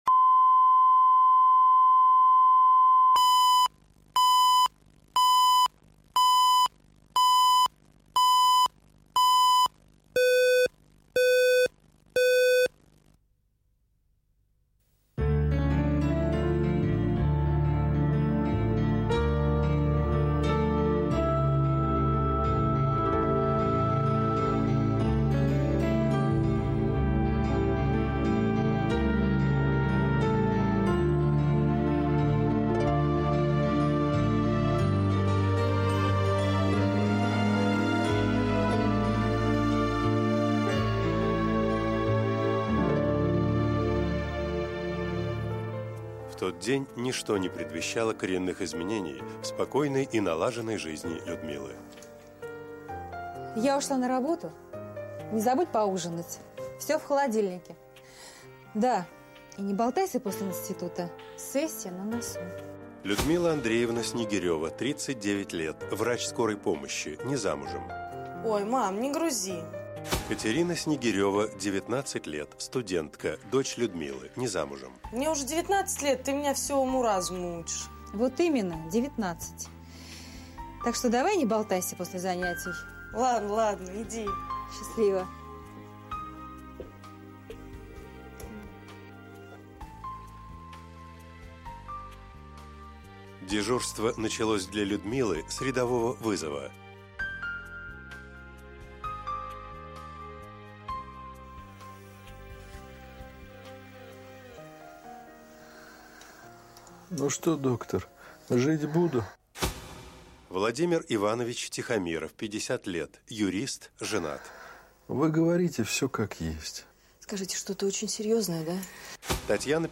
Аудиокнига Искупление вины | Библиотека аудиокниг